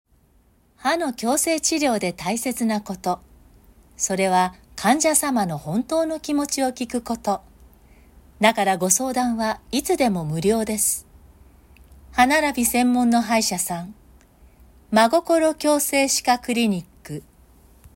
ナレーション音源サンプル　🔽
【安心系ナレーション】